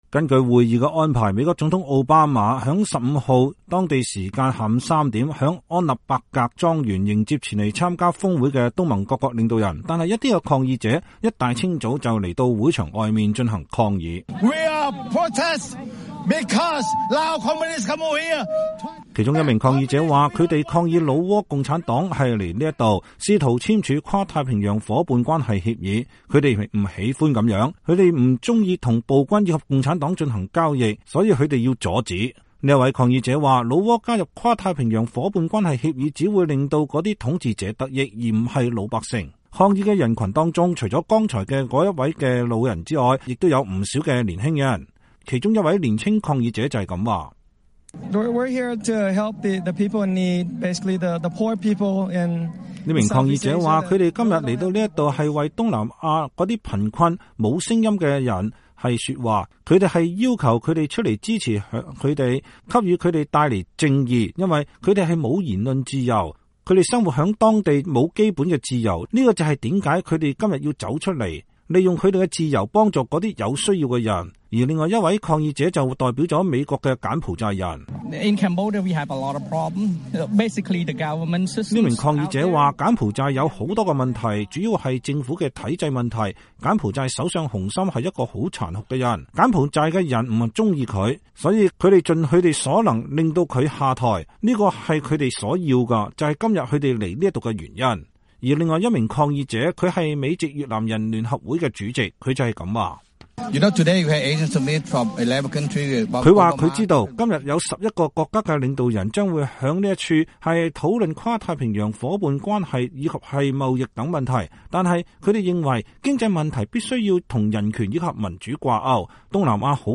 抗議的人群中除了剛才的那位老者，也有不少年輕人。